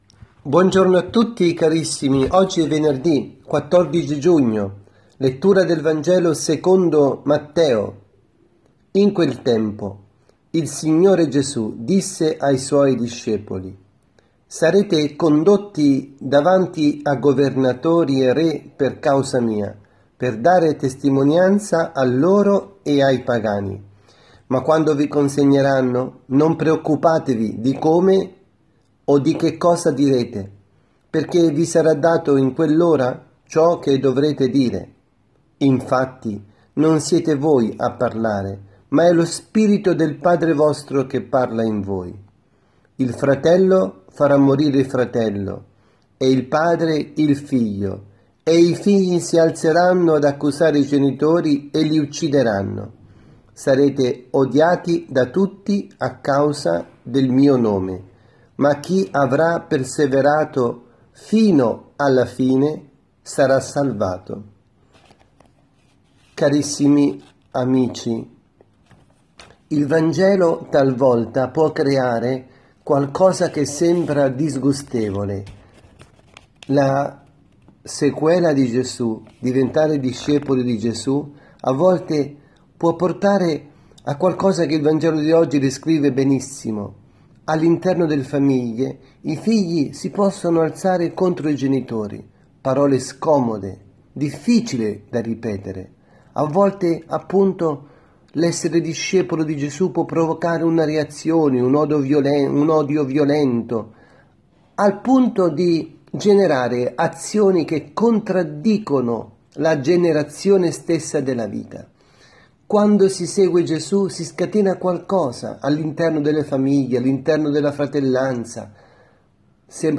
Omelia
dalla Casa di Riposo Santa Marta – Milano